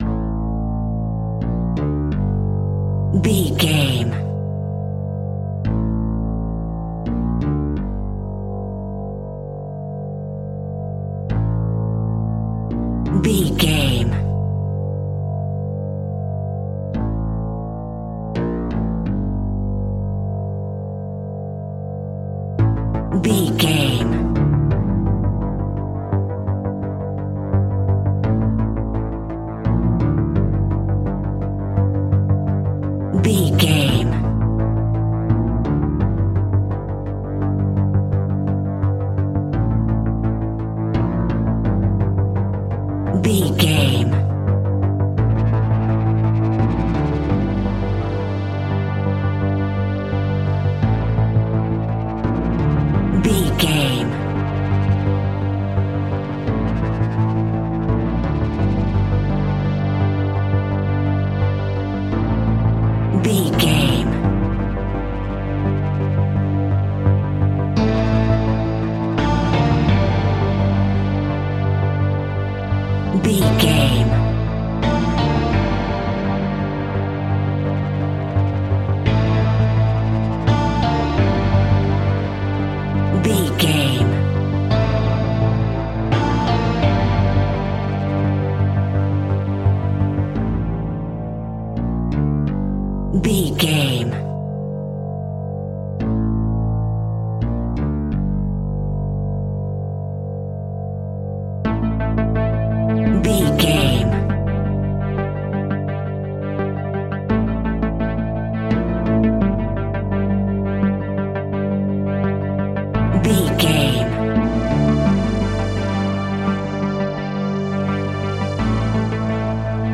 In-crescendo
Thriller
Aeolian/Minor
ominous
eerie
horror music
Horror Pads
horror piano
Horror Synths